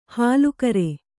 ♪ hālu kare